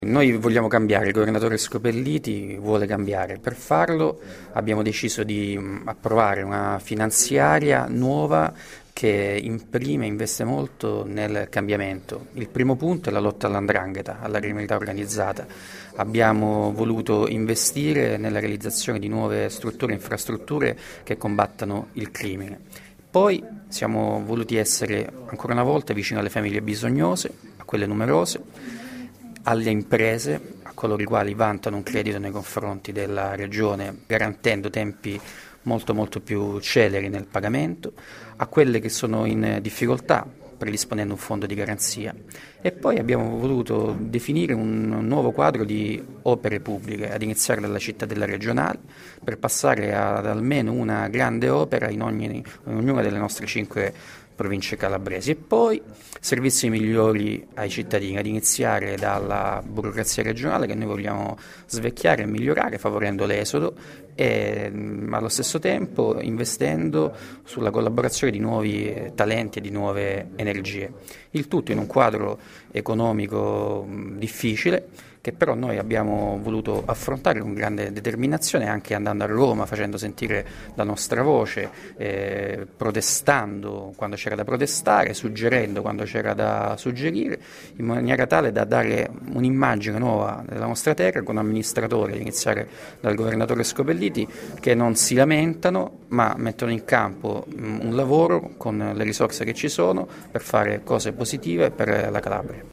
Nella sede di palazzo “Alemanni” il Presidente della Regione Giuseppe Scopelliti e l’Assessore Giacomo Mancini hanno illustrato alla stampa il Bilancio regionale di previsione 2011.
Ascolta l’intervento dell’Assessore Mancini qui